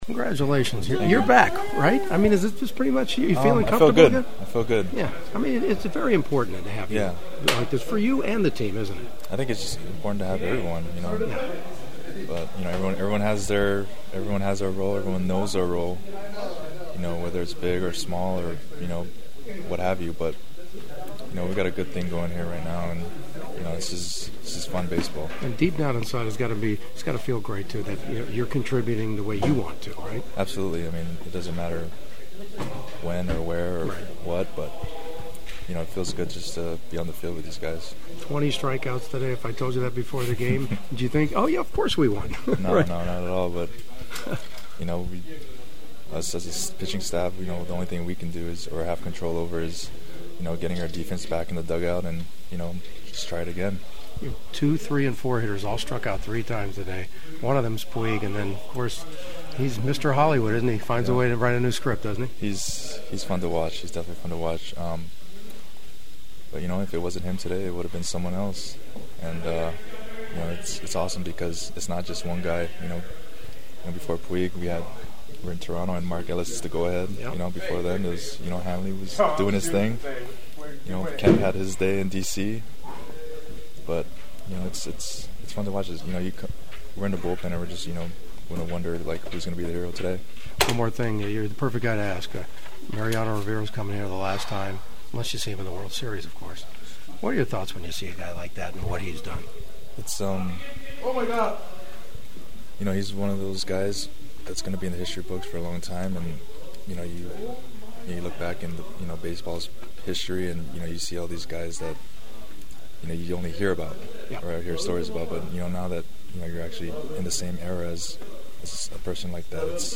The following are my postgame clubhouse interview including some preview thoughts on the Yankees invading Dodger Stadium for 2 nights starting on Tuesday.
Dodgers reliever Brandon League who’s awakened with 3 wins in a week: